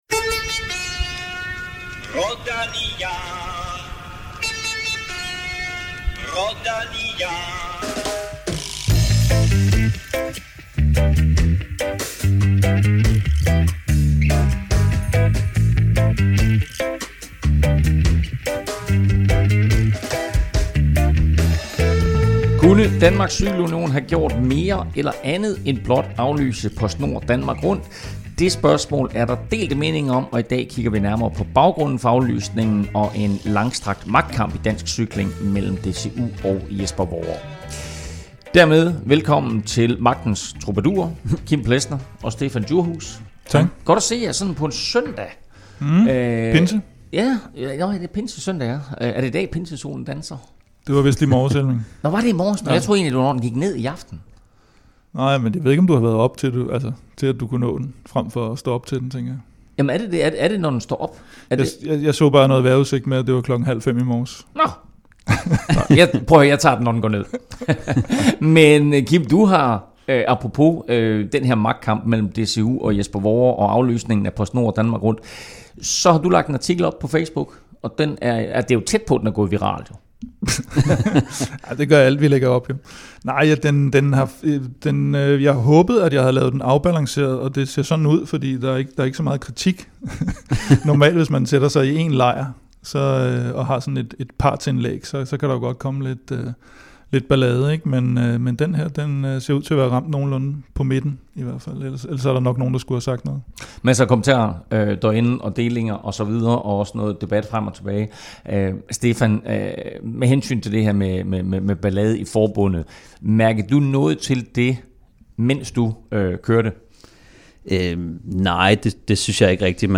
PostNord Danmark Rundt er aflyst. Kritikerne mener, at det er en falliterklæring af DCU, der formentlig har aflyst netop for ikke at risikere at gå fallit. Vi tegner et billede af magtkampen mellem Jesper Worre og DCU og har den forsvarende vinder af PostNord Danmark Rundt i studiet, nemlig Niklas Larsen. Vi skal også høre fra en anden tidligere vinder, Kurt Asle Arvesen, som, efter en lang periode hos Team Sky, nu er sportsdirektør for Niklas Larsen på norske UnoX.